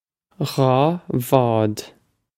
Pronunciation for how to say
ghaw vawd
This is an approximate phonetic pronunciation of the phrase.